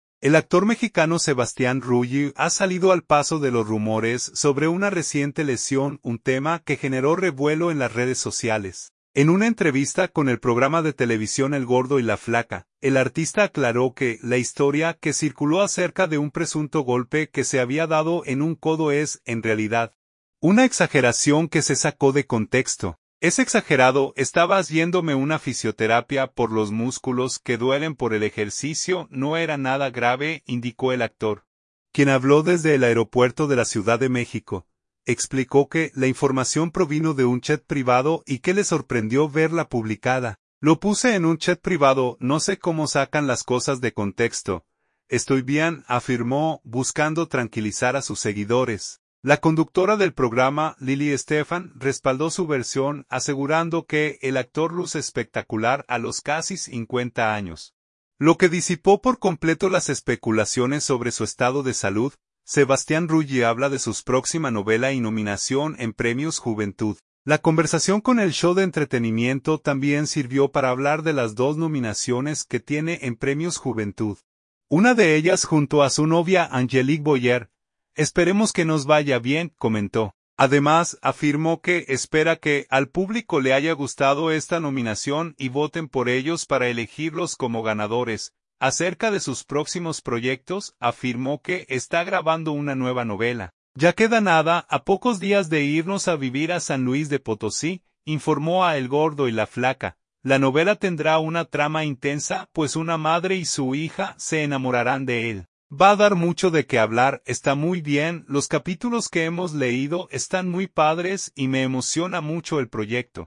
En una entrevista con el programa de televisión El Gordo y la Flaca, el artista aclaró que la historia que circuló acerca de un presunto golpe que se había dado en un codo es, en realidad, una exageración que se sacó de contexto.
“Es exagerado, estaba haciéndome una fisioterapia por los músculos que duelen por el ejercicio, no era nada grave”, indicó el actor, quien habló desde el aeropuerto de la Ciudad de México.